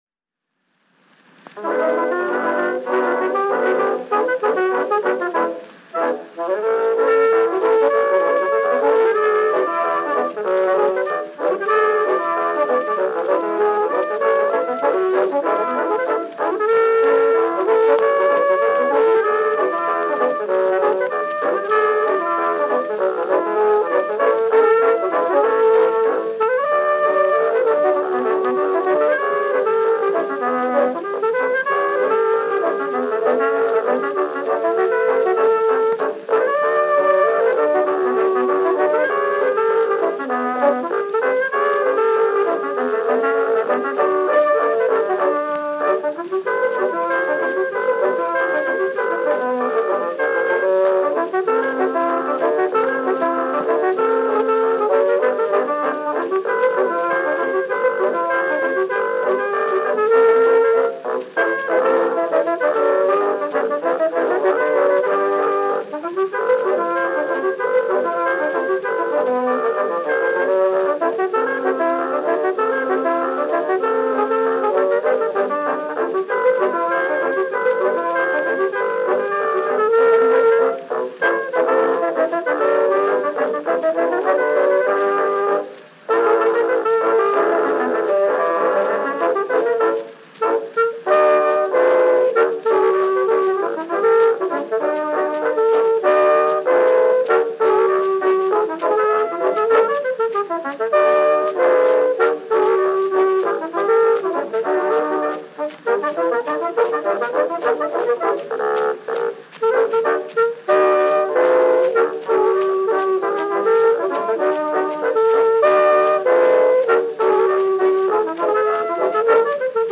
. fox trot
Instrumental selection.
Popular instrumental music—1911-1920.
Saxophone music.